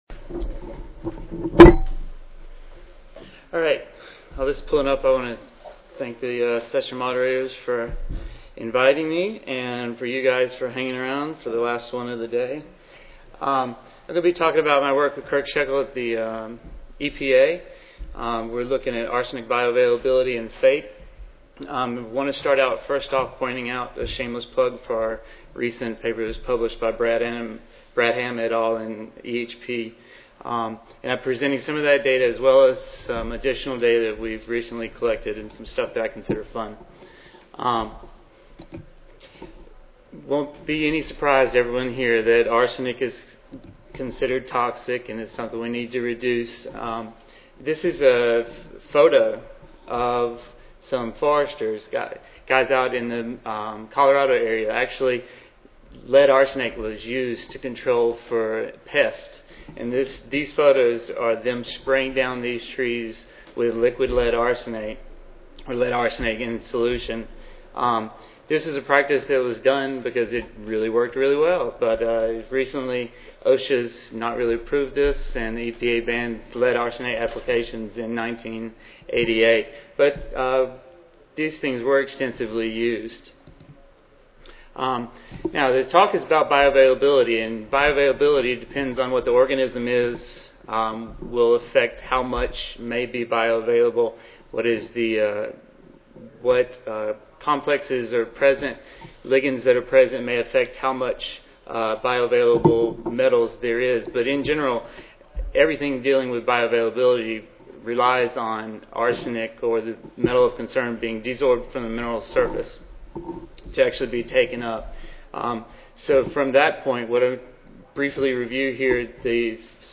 US EPA Recorded Presentation Audio File